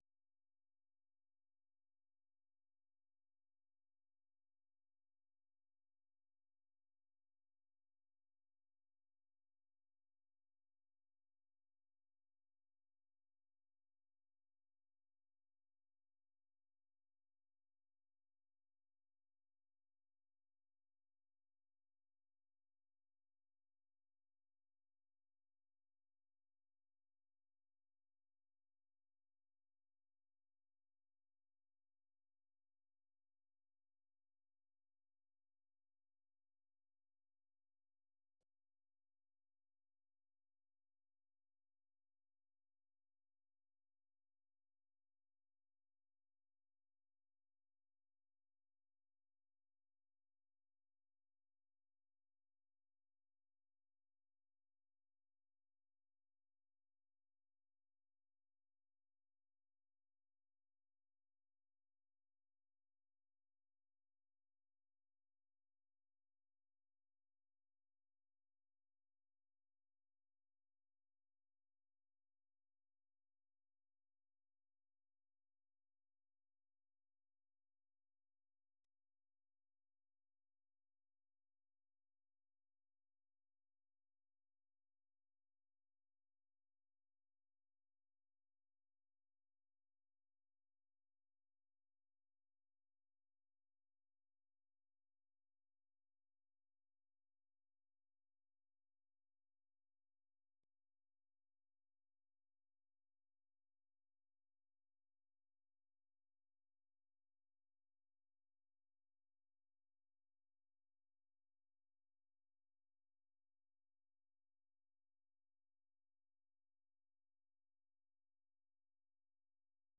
ሐሙስ፡-ከምሽቱ ሦስት ሰዓት የአማርኛ ዜና